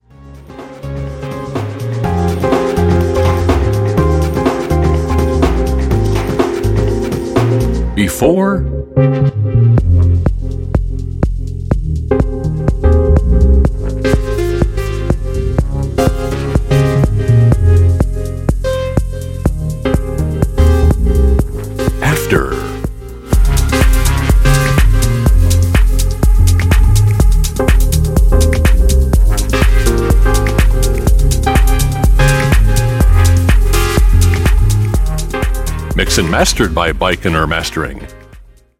Bass / Tech